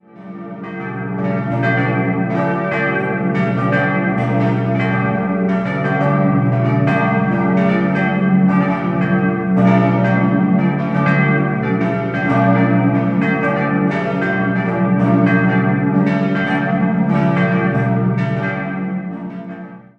September 1630 geweiht werden. 5-stimmiges Geläute: b°-d'-f'-g'-a' Die Glocken 1 und 3 wurden 1878 von J. Grassmayr gegossen.